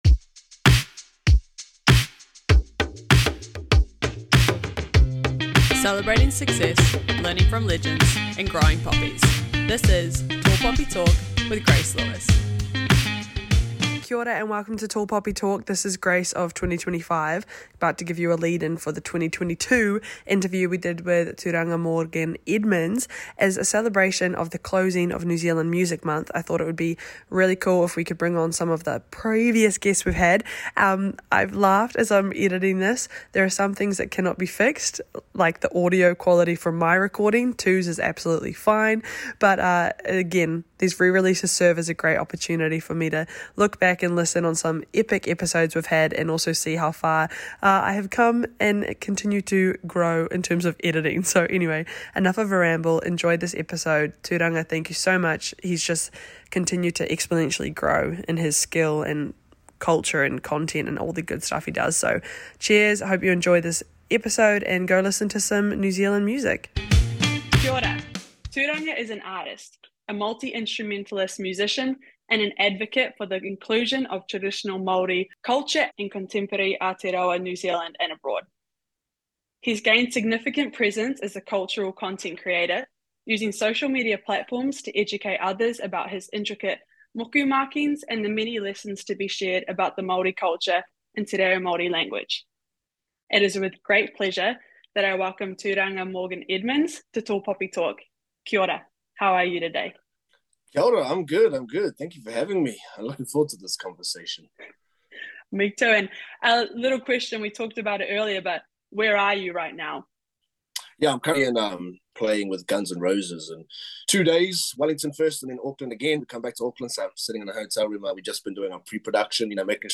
Listen to the full interview on Spotify, iHeart Radio, or Apple Podcasts.